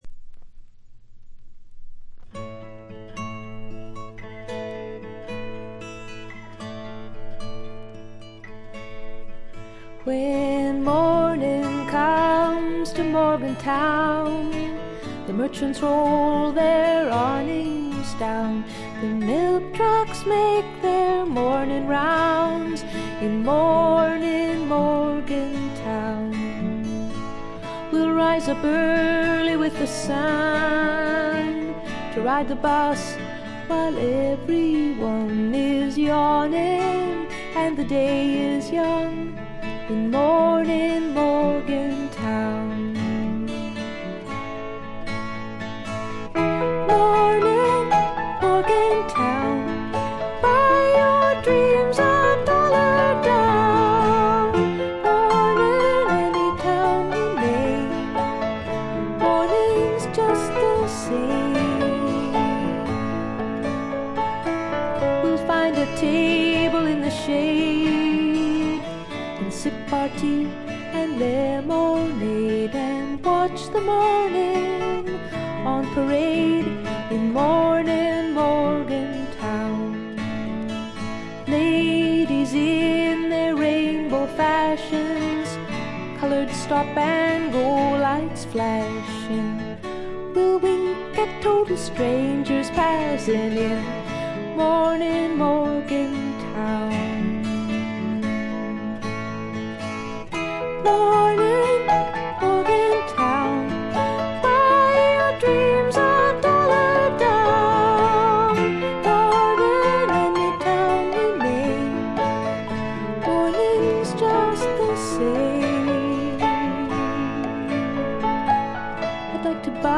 軽微なバックグラウンドノイズ、チリプチ。散発的なプツ音少し。
試聴曲は現品からの取り込み音源です。